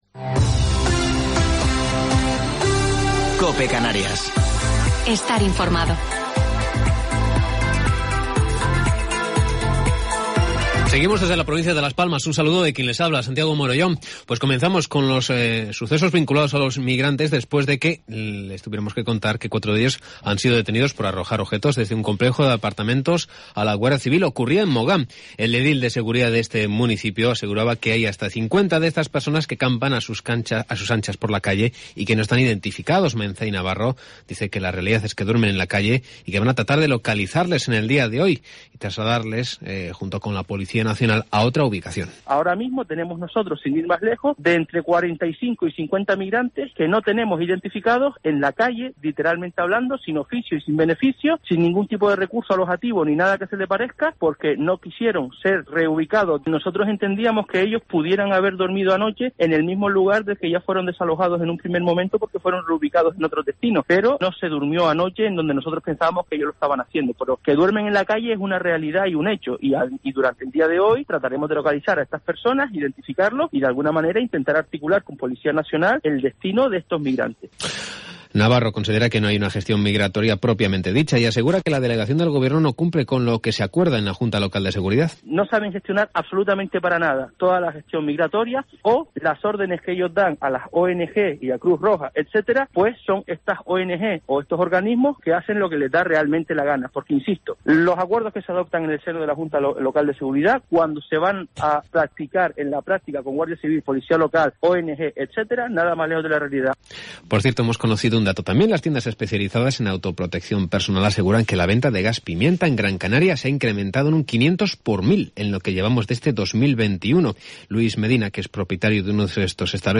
Informativo local 10 de Febrero del 2021